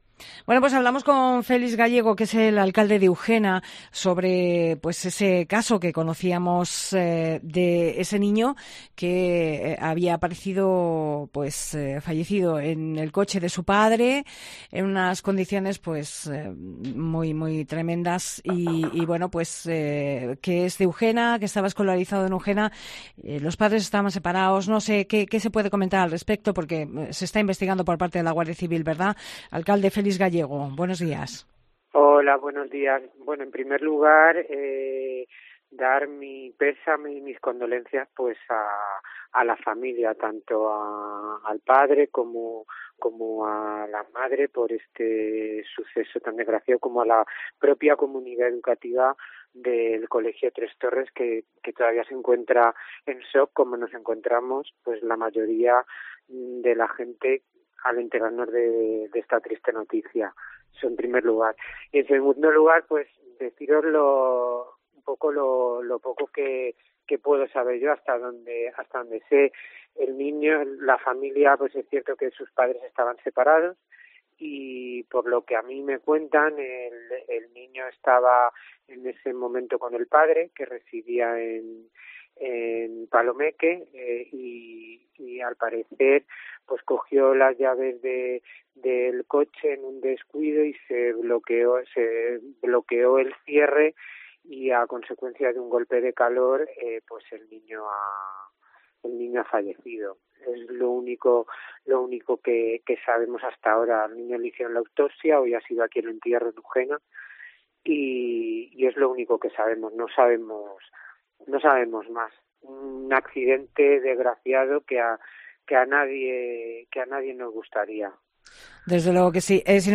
Entrevista al alcalde de Ugena
AUDIO: Entrevista al alcalde de Ugena, Félix Gallego